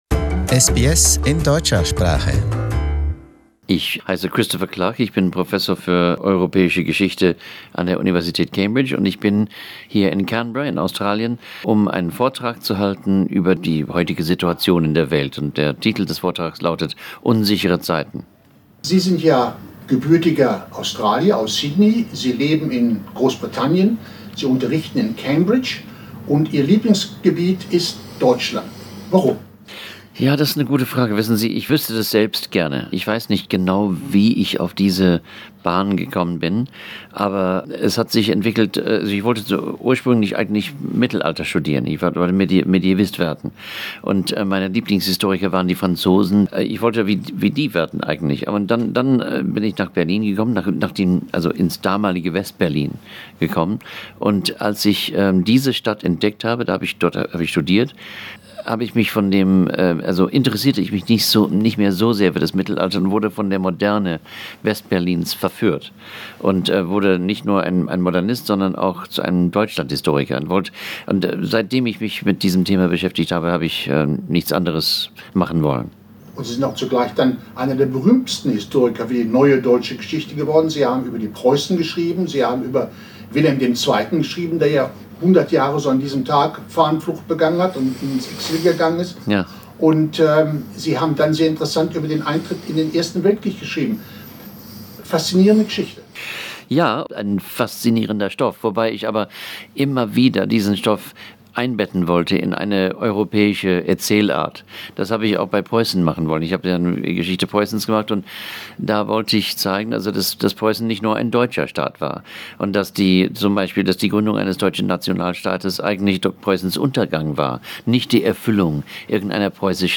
Konrad Adenauer Lecture - Uncertain Times. Germany, Europe and the World Order